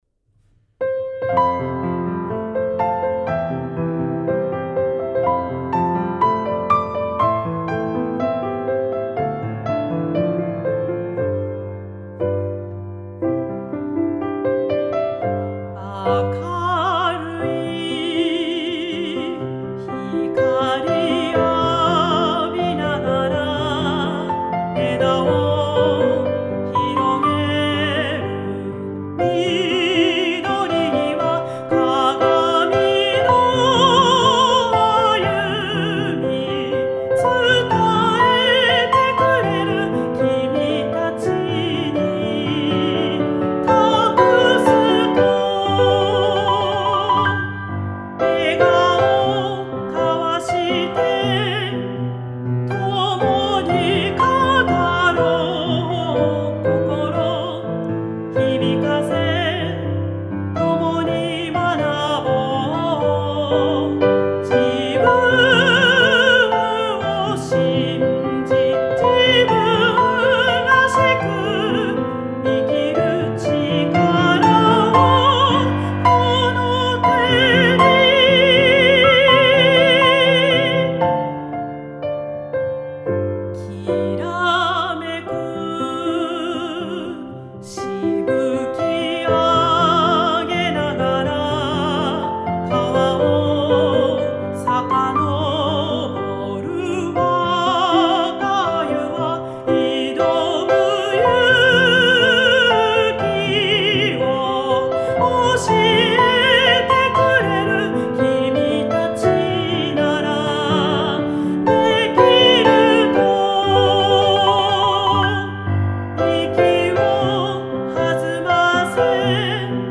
校歌
伸びやかな曲調で、令和の時代にふさわしい校歌となっています。